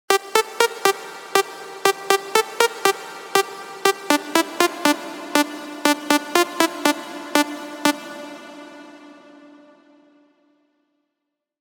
Inside, you’ll find all of the essentials from punchy basses, powerful drones, lush pads, and much more.